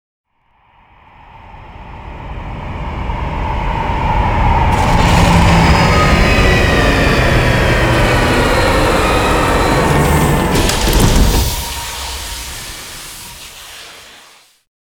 landing3.wav